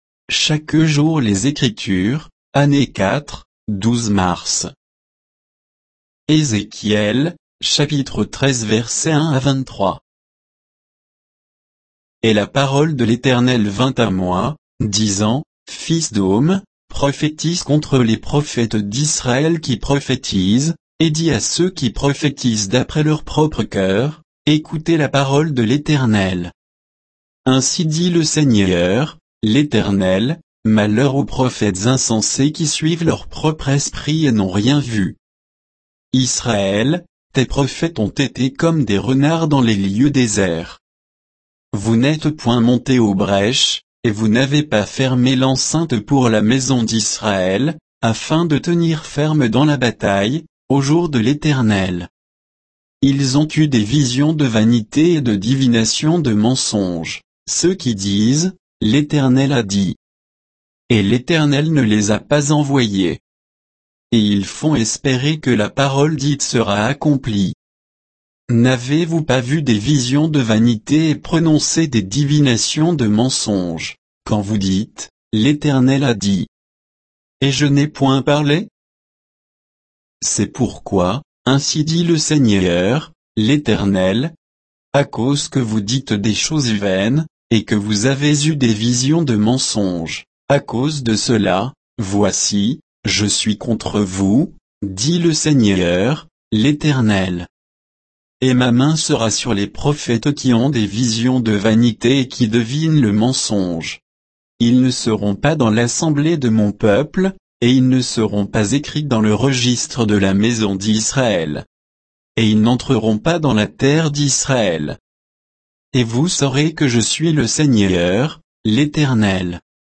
Méditation quoditienne de Chaque jour les Écritures sur Ézéchiel 13